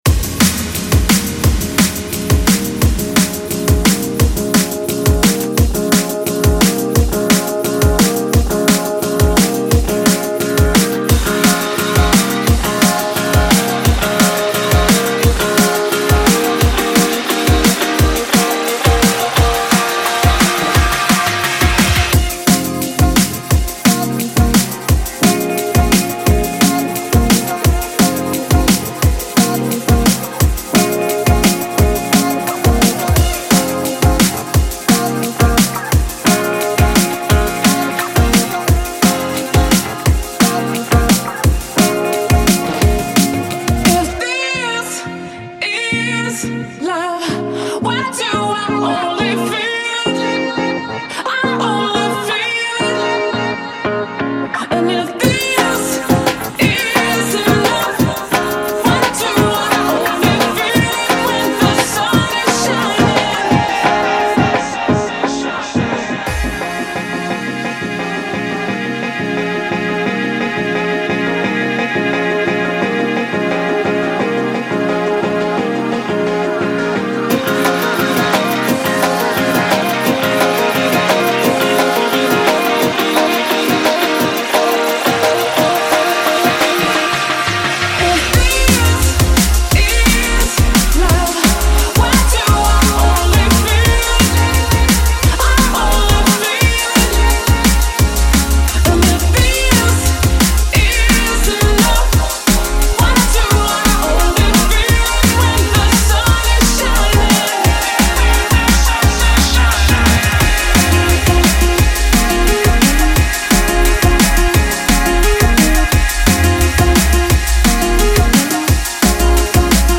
Broadcast live